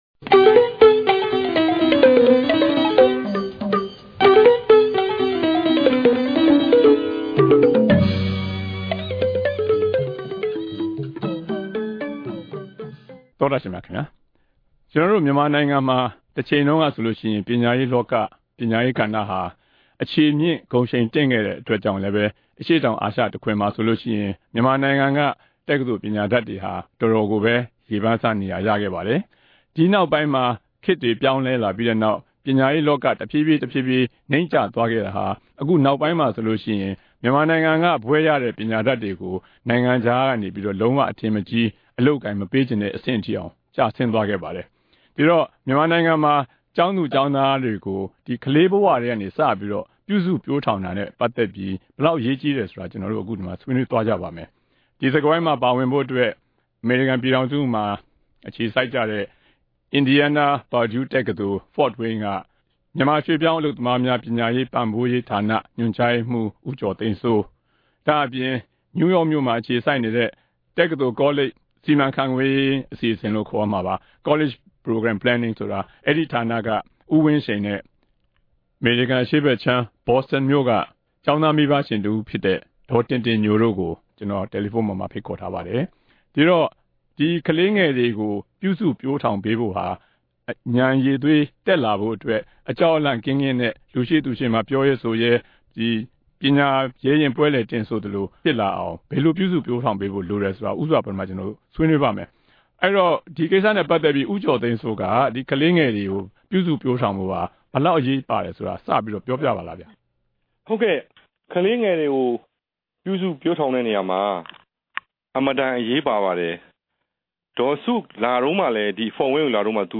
အခုတပတ် တနင်္ဂနွေ စကားဝိုင်းအစီအစဉ်မှာ ကျောင်းသားကျောင်းသူတွေ ပညာရှာဖွေကြတဲ့အခါ ဉာဏ်ရည်ဖွံ့ဖြိုးစေဖို့ ဘယ်လိုလိုအပ်ချက်တွေ ရှိသင့်တယ်။ ဒီနိုင်ငံတွေမှာ ဘယ်လိုကျင့်သုံး လုပ်ကိုင်နေတယ်ဆိုတာတွေကို ဆွေးနွေးတင်ပြထားပါတယ်။